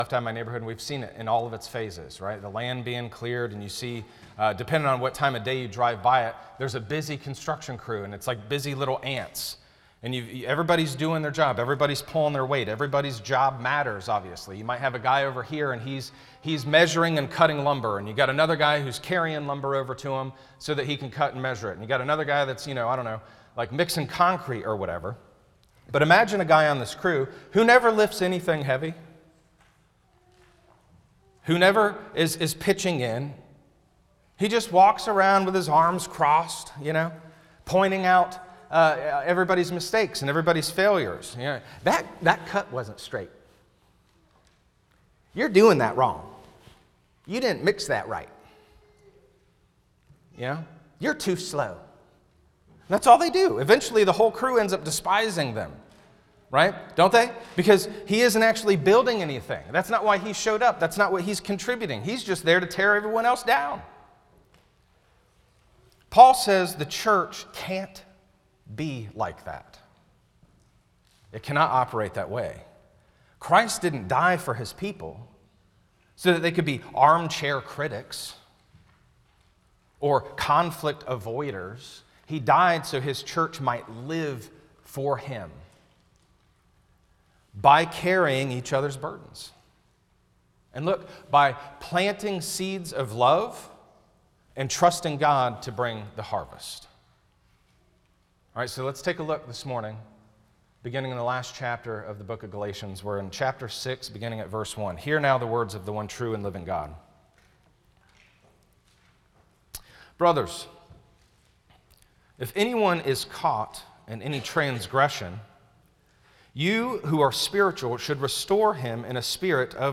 Sermons Podcast - Bearing One Another's Burdens | Free Listening on Podbean App